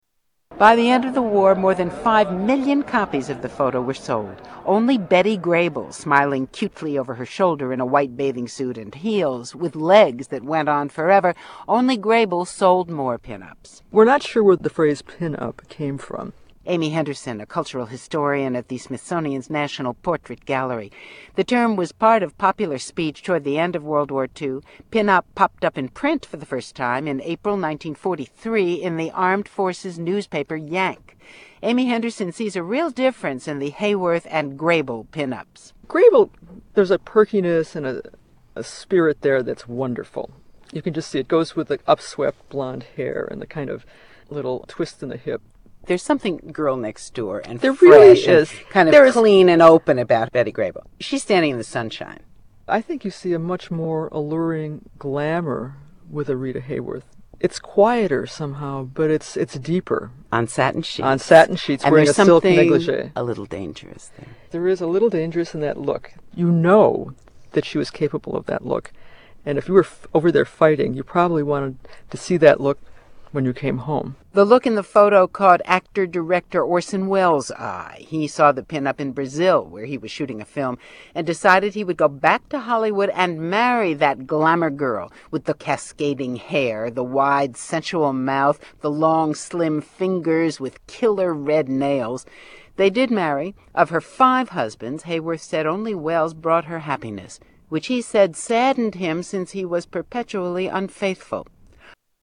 NPR report on Rita Hayworth 3